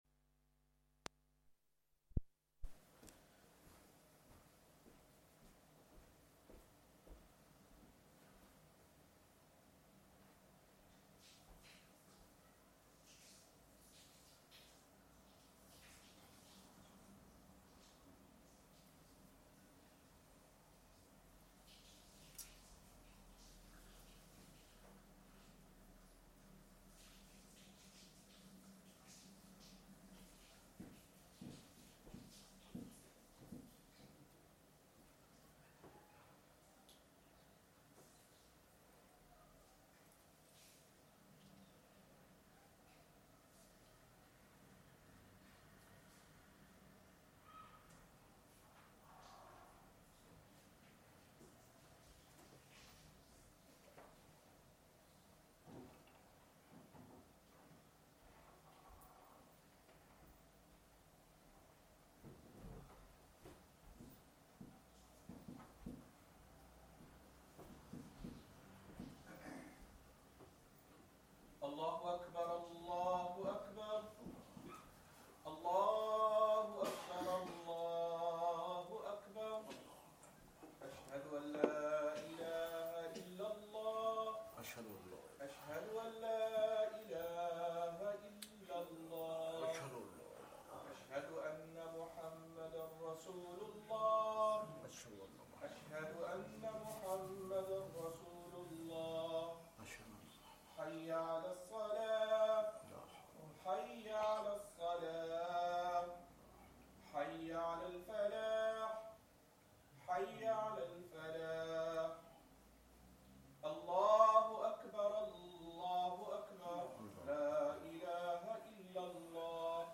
Madni Masjid, Langside Road, Glasgow